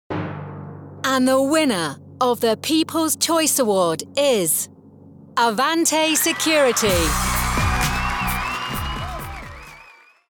English (British)
Announcements
My voice has been describe as relatable, rich, assured, smooth, confident and trusting.
Professional vocal booth with wireless keyboard and mouse.
Focsurite Scarlett 2i2, D2 Synco shotgun microphone, and twisted wave recording software.
Pitch
Low
ConversationalSmoothTrustworthyAssuredConfidentWarmAuthoritativeRelatableFriendlyProfessional